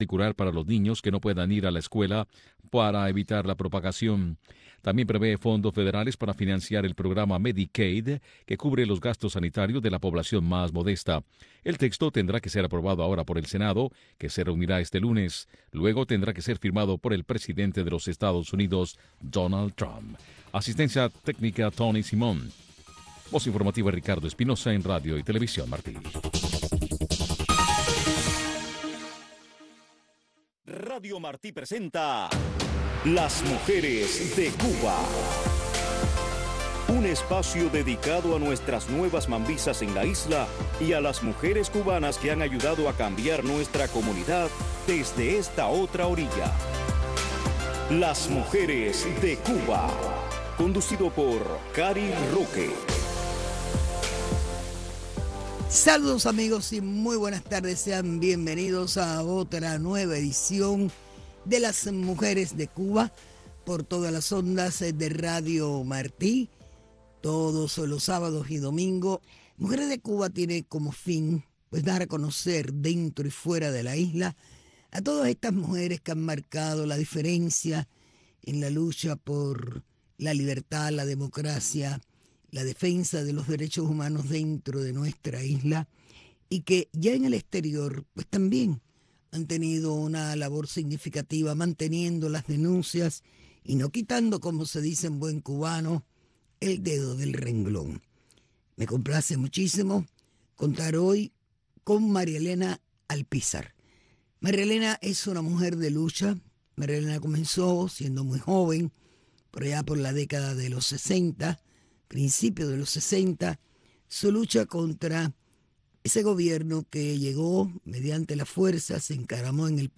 Un programa que busca resaltar a las mujeres cubanas que marcan pauta en nuestra comunidad y en la isla. Y es un acercamiento a sus raíces, sus historias de éxitos y sus comienzos en la lucha contra la dictadura. Un programa narrado en primera persona por las protagonistas de nuestra historia.